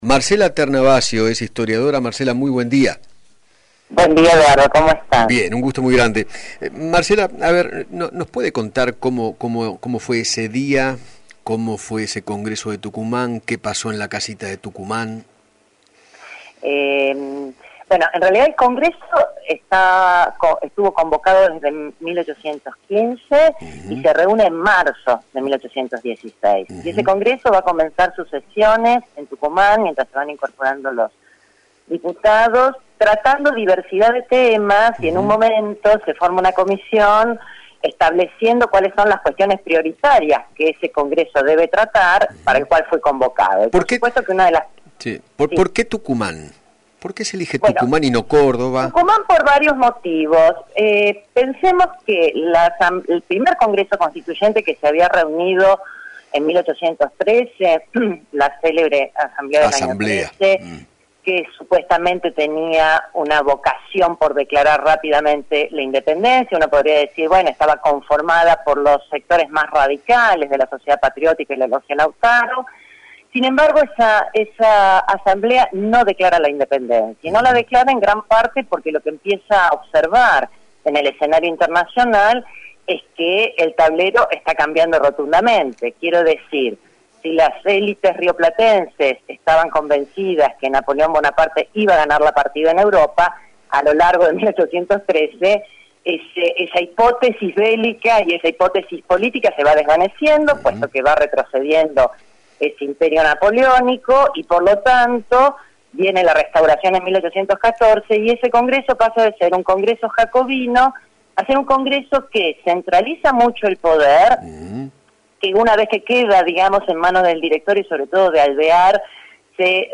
La historiadora Marcela Ternavasio dialogó con Eduardo Feinmann e hizo un repaso histórico acerca del proceso hacia la declaración de la independencia, que comenzó en mayo de 1810 y concluyó el 9 de julio de 1816, cuando los diputados de las Provincias Unidas del Río de la Plata se reunieron en el Congreso de Tucumán.